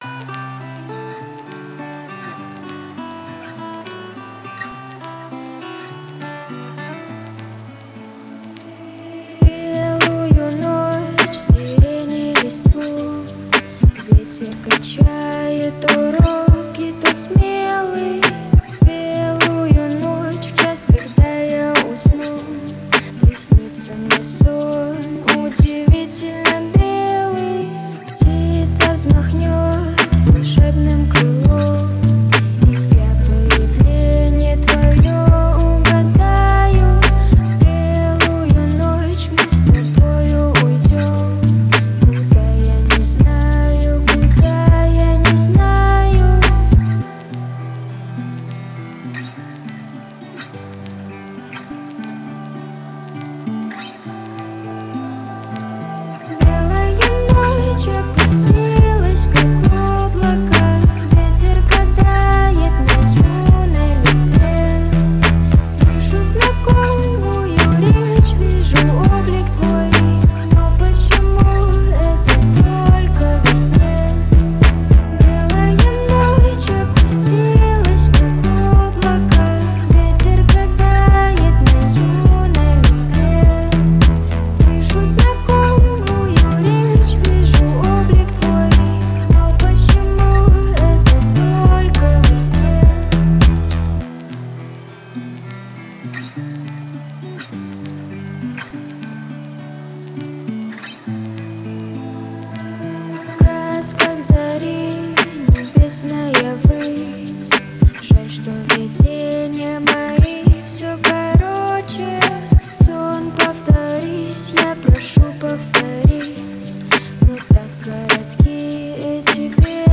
Категория: Спокойная музыка
спокойные песни